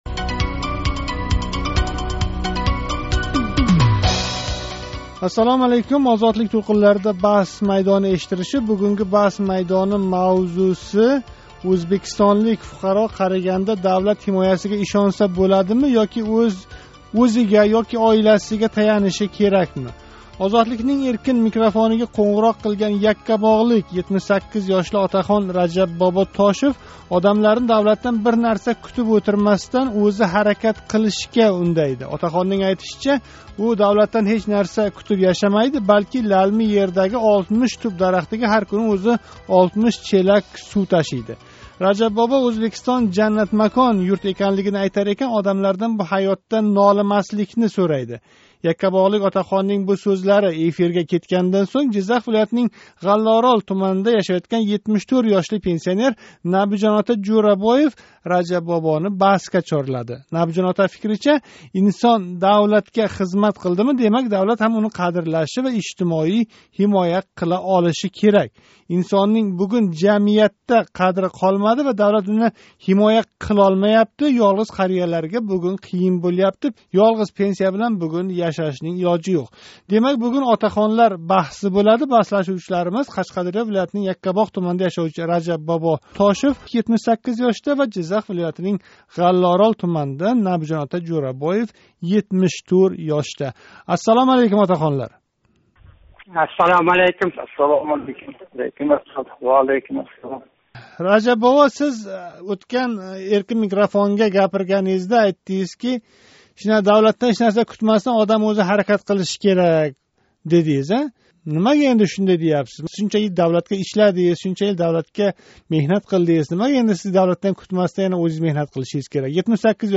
Бундай зеҳният давлатнинг ўз фуқаролари олдидаги фундаментал мажбуриятини деярли йўқ ҳолга келтирмадими? Бу мавзу атрофида Яккабоғ ва Ғаллаоролда яшовчи 78 яшар отахонлар баҳслашди.